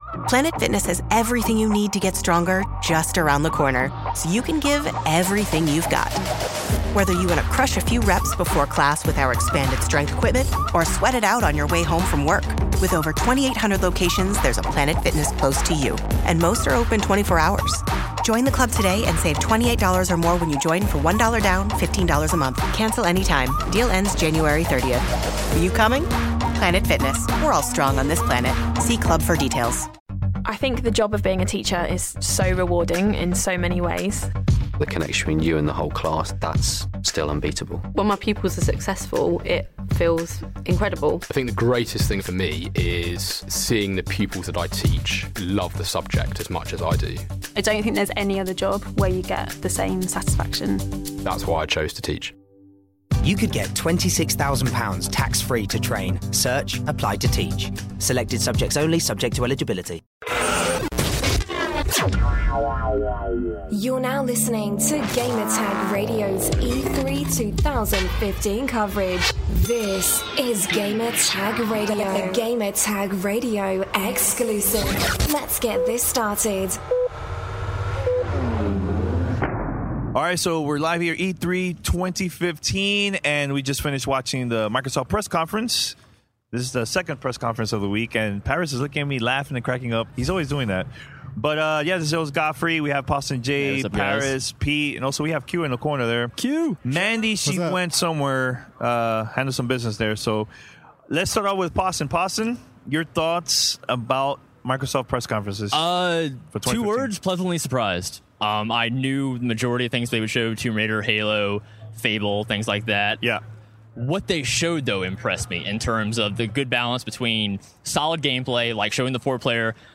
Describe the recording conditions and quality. The GTR crew recaps the Xbox 2015 E3 Press Conference live from the Galen Center in Los Angeles, CA.